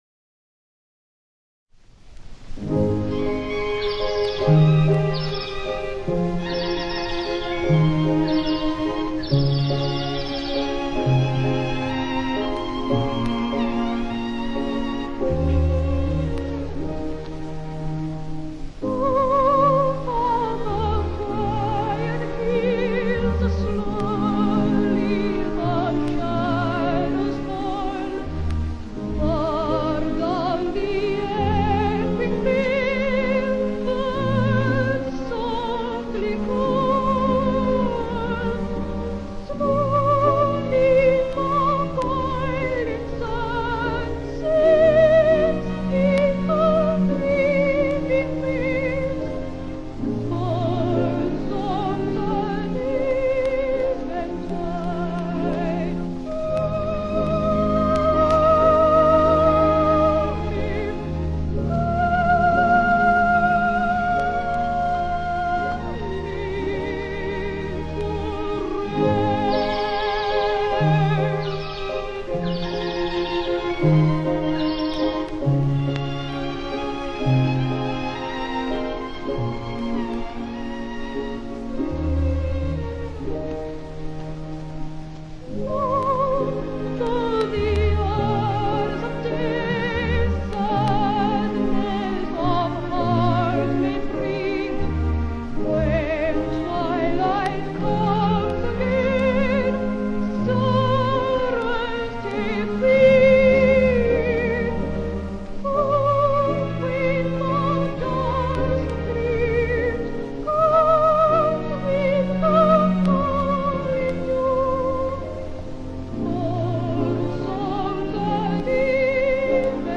at Grand Hotel, Eastbourne